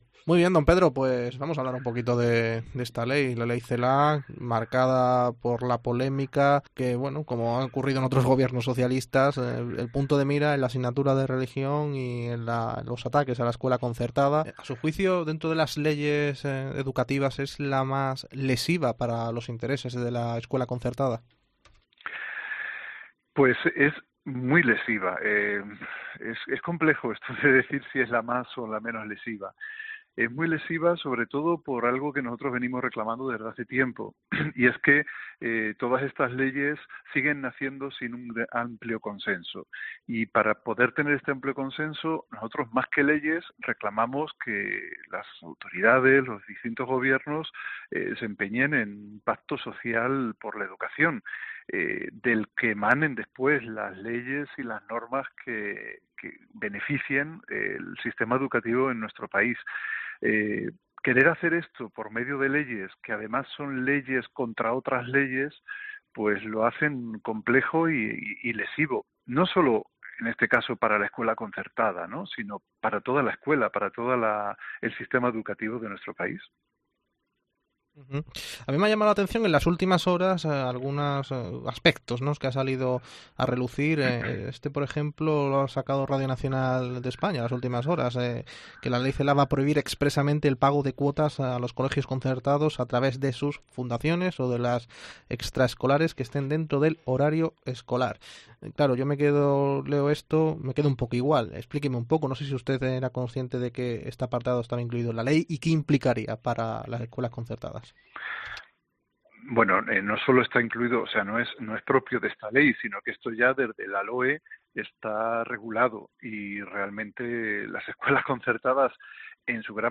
Educación concertada entrevista